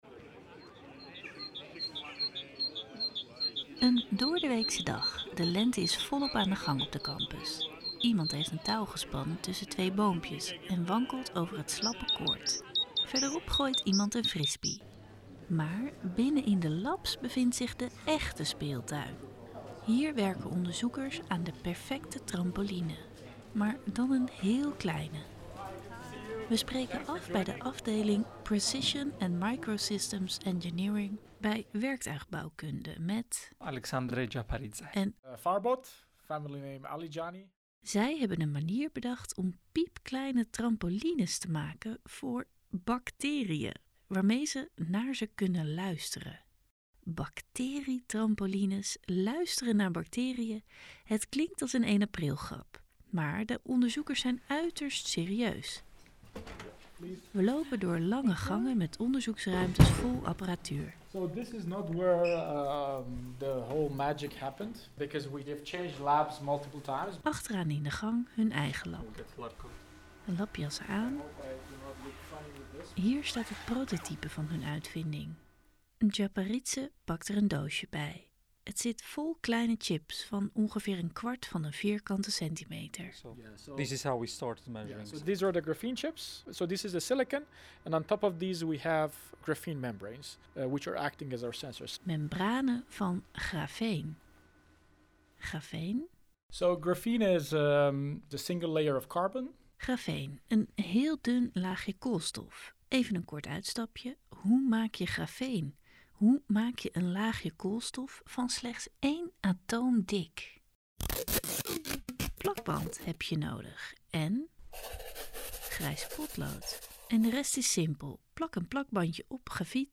Je hoort het in het audioverhaal ‘Luisteren naar bacteriën’.